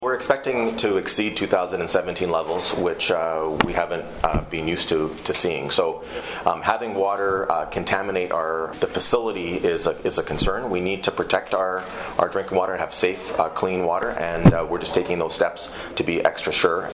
Belleville Mayor Mitch Panciuk gave an update on Wednesday morning at City Hall as to what steps are being taken by the city and their Emergency Management Committee in regards to the high water levels being experienced.